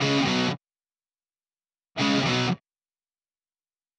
Power Pop Punk Guitar Intro 01.wav